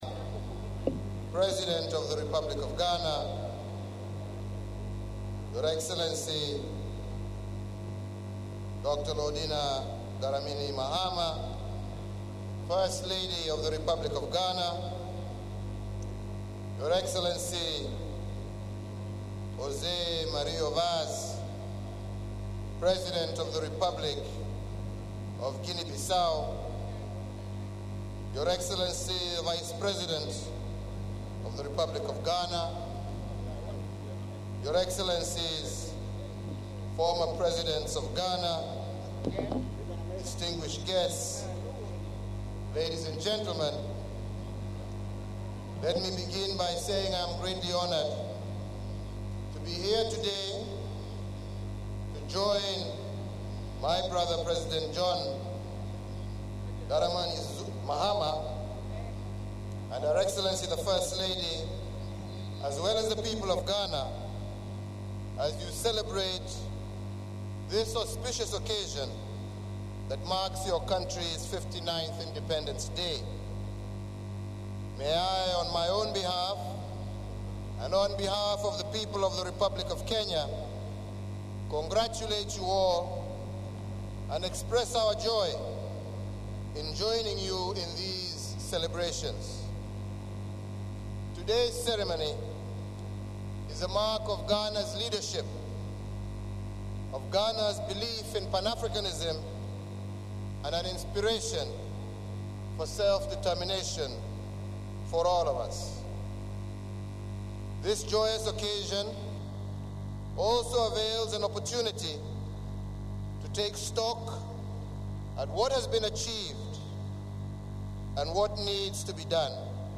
The President of Kenya, Uhuru Kenyatta on Sunday morning addressed Ghana’s 59th Independence anniversary celebrations at the Black Star Square.
uhuru_kenyatta_at_ghana_indece_anniversary.mp3